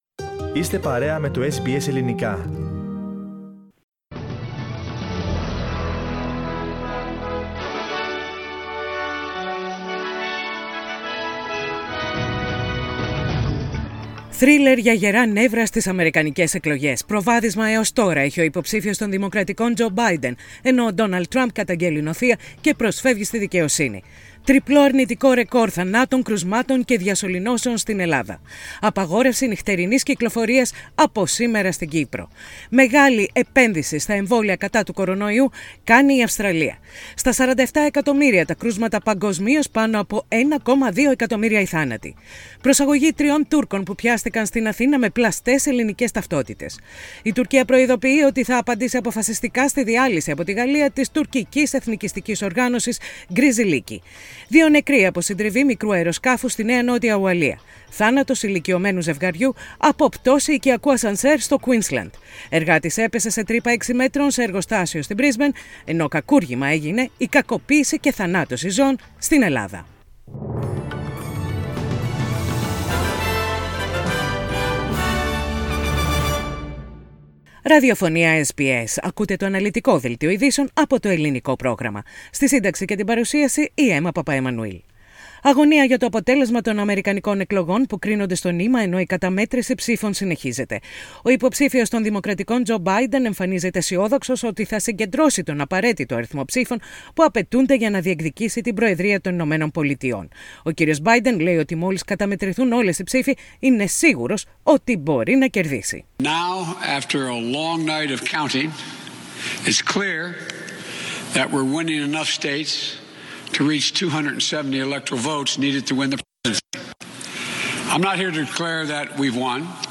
The main news of the day from the Greek program of SBS radio.